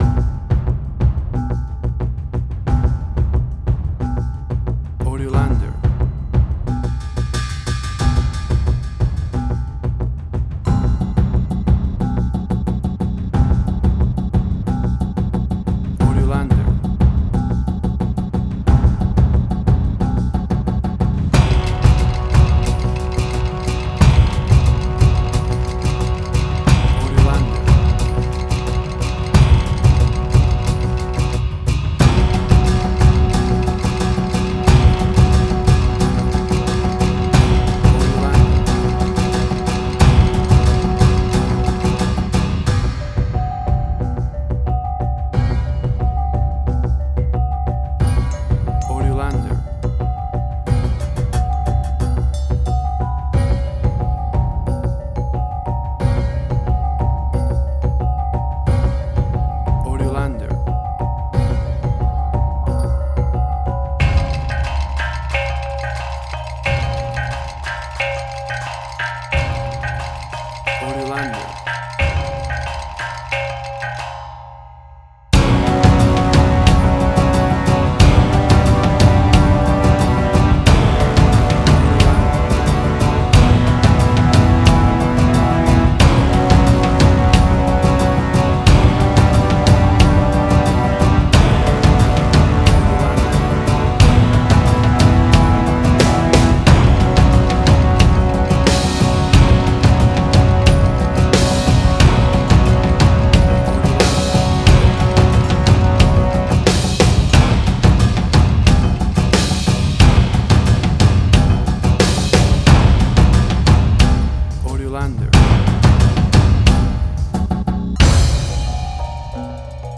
Gamelan Ethnic instrumental.
Tempo (BPM): 90